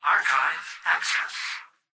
"Archive access" excerpt of the reversed speech found in the Halo 3 Terminals.